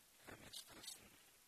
Pronunciation: ka:məsta:stn